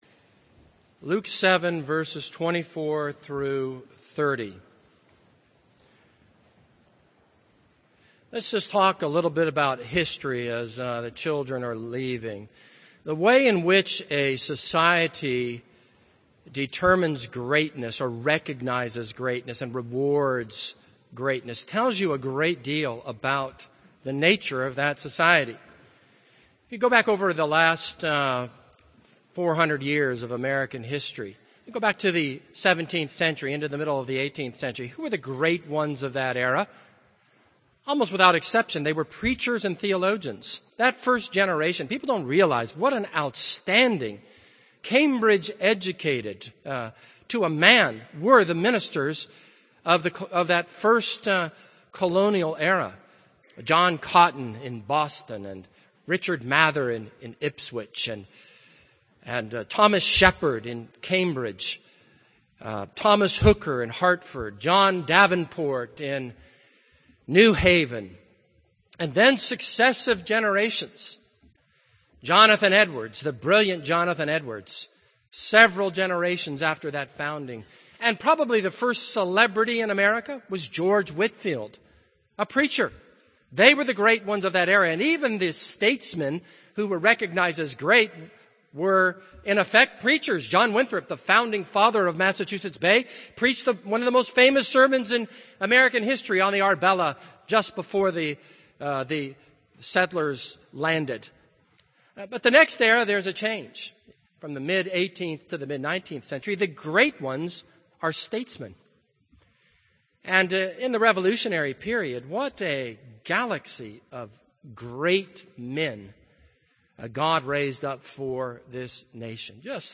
This is a sermon on Luke 7:24-30.